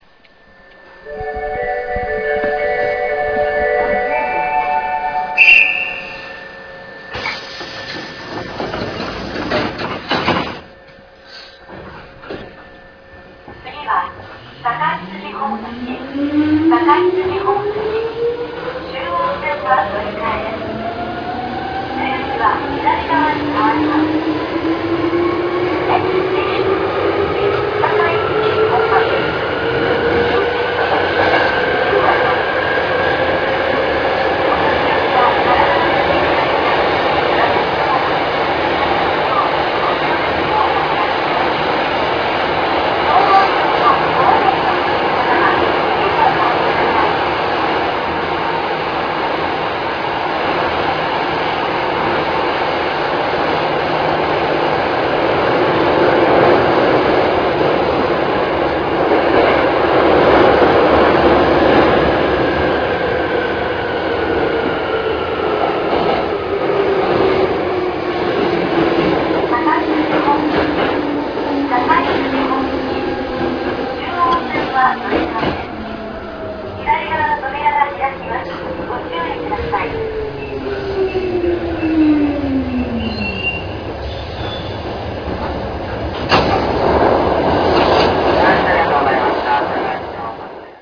走行音[hrr3300a.ra/177KB]
制御方式：抵抗制御(ES583型)
主電動機：130kW×4(歯車比5.25)